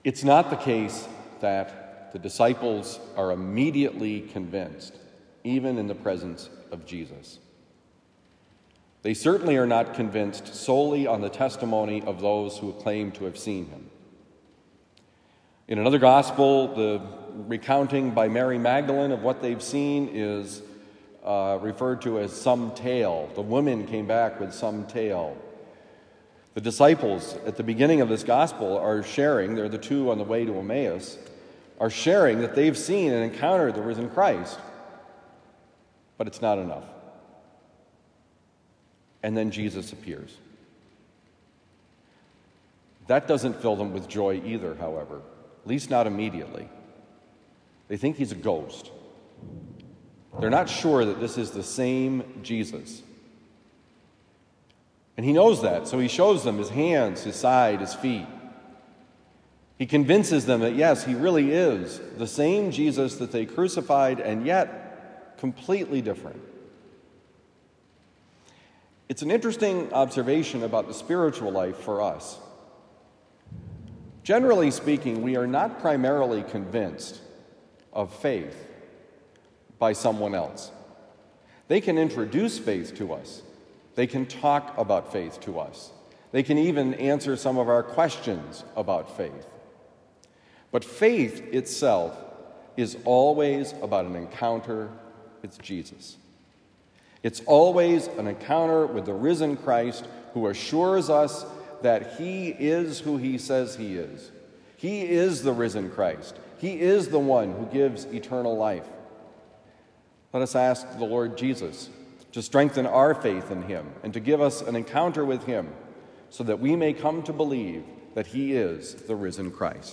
Given at Christian Brothers College High School, Town and Country, Missouri.